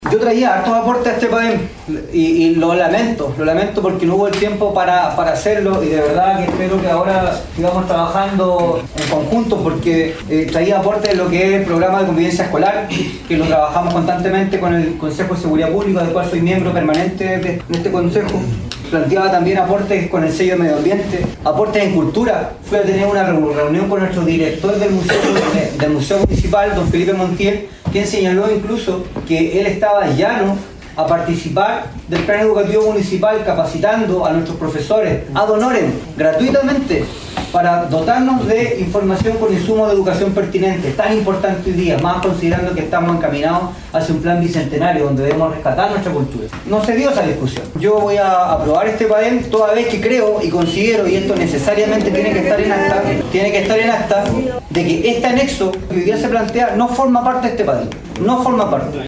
Finalmente, el Concejal Enrique Soto, aprobó el Padem, sin embargo, lamentó que no se haya dado la posibilidad de discutir algunos aspectos que podrían haber sido considerados: